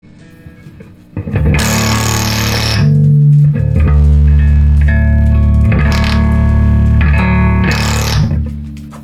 PS-2A Clipping/Unnatural Distortion Issue with Fender '68 Custom Twin Reverb
Fender 68 Twin reissue Nasty HF Oscillation